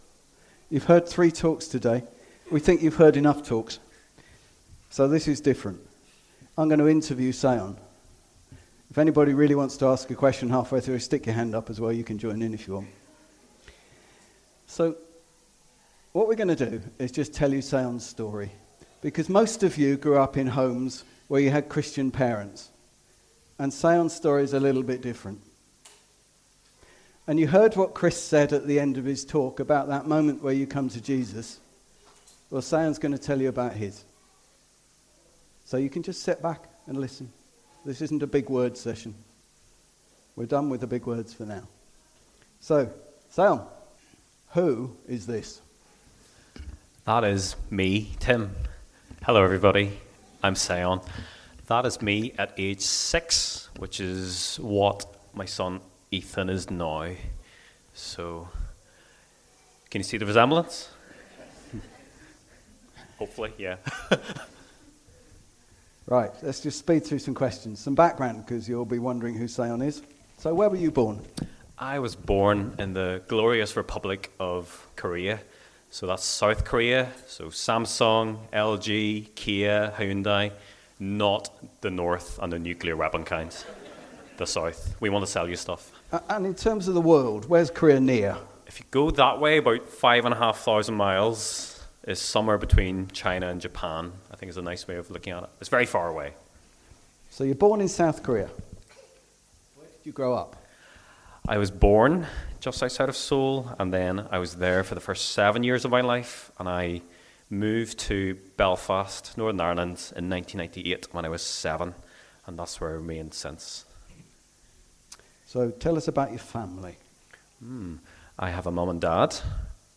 Join us for an honest and inspiring conversation about faith, perseverance, and the power of God’s calling.